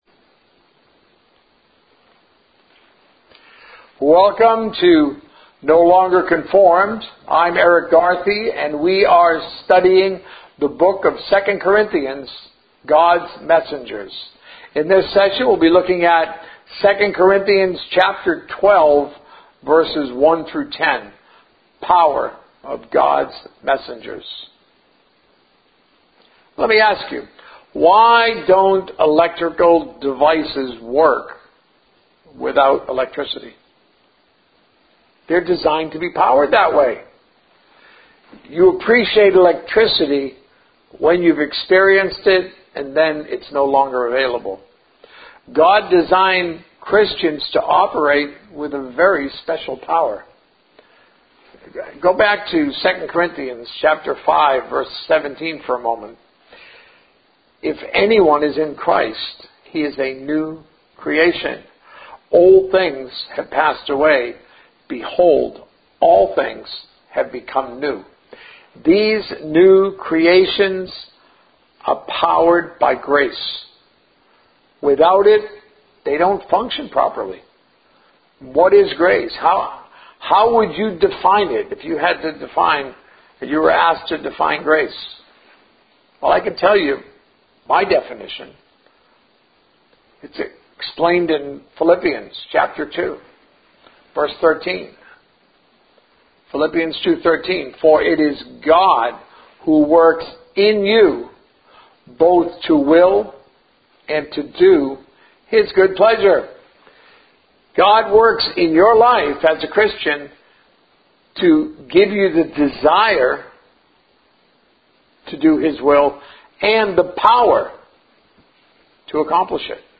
A message from the series "Practical Christianity." Learning to relate to one another